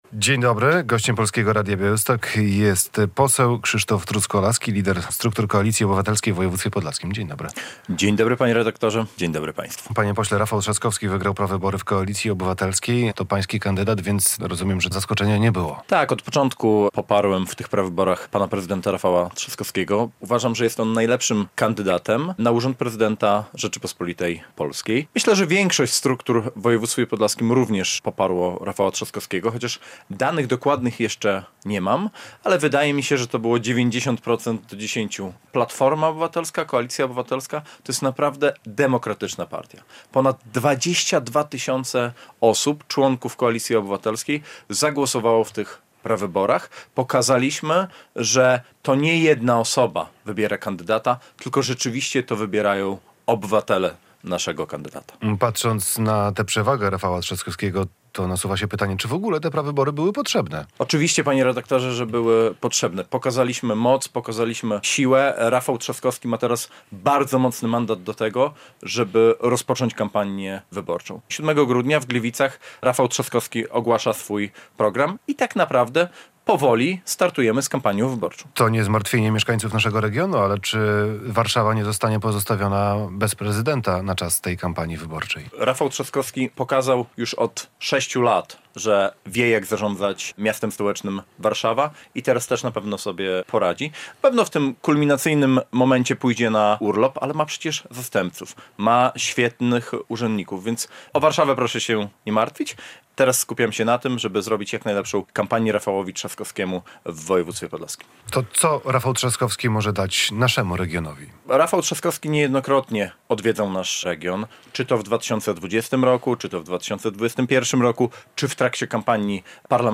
Radio Białystok | Gość | Krzysztof Truskolaski - poseł, szef podlaskiej Platformy Obywatelskiej